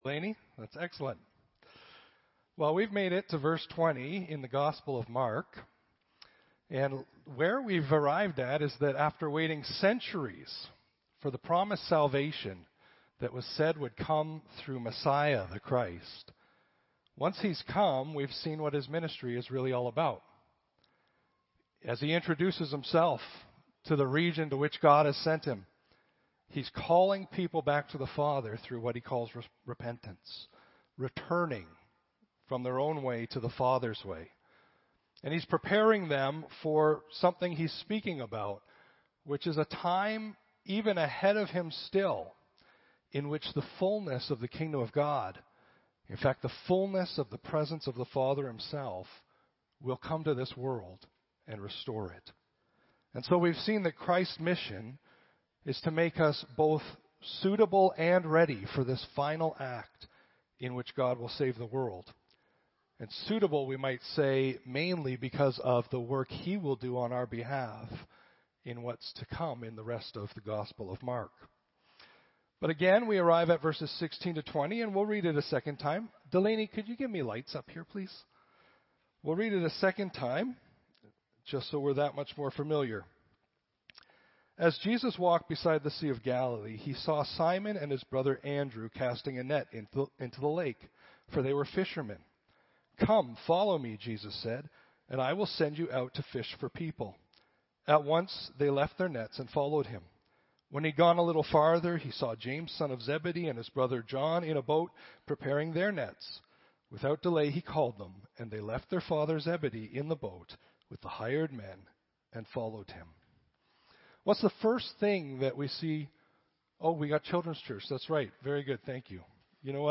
Audio Sermon Library The Gospel of Mark, Part 15-A Kingdom of Us.